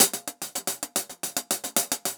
Index of /musicradar/ultimate-hihat-samples/110bpm
UHH_AcoustiHatC_110-01.wav